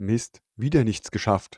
neutral.wav